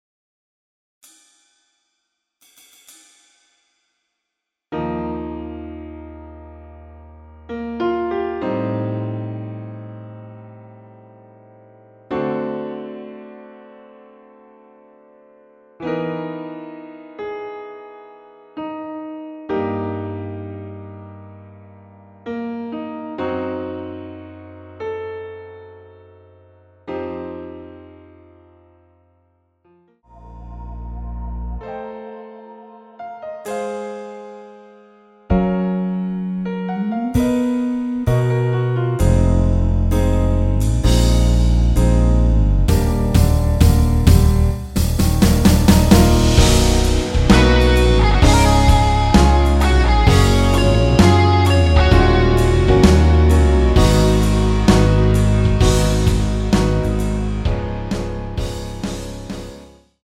원키에서(-5)내린 MR입니다.
Eb
앞부분30초, 뒷부분30초씩 편집해서 올려 드리고 있습니다.
중간에 음이 끈어지고 다시 나오는 이유는